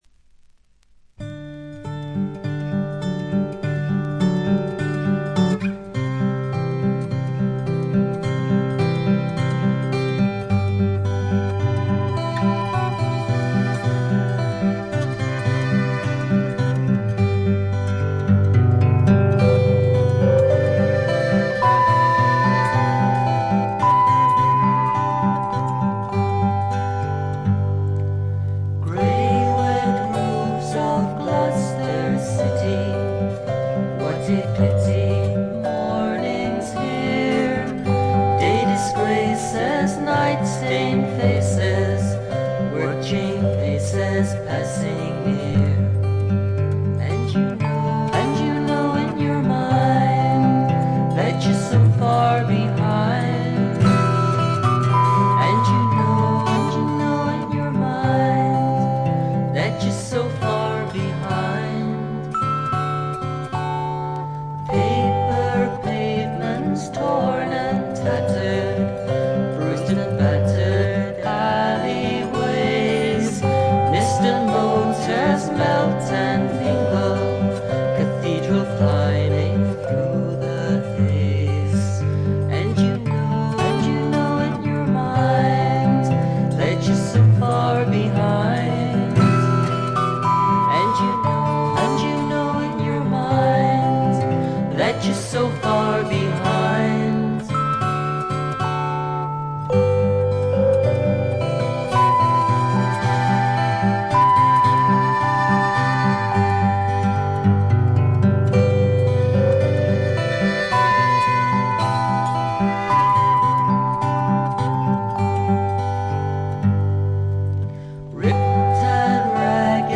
軽微なプツ音少々、静音部でわずかなチリプチ。
ギター、マンドリン、ダルシマー等のアコースティック楽器のみによるフォーク作品。
試聴曲は現品からの取り込み音源です。